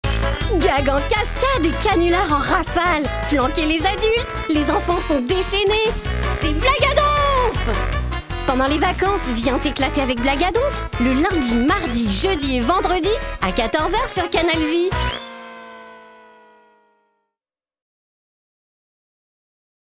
Voix off
bande-annonce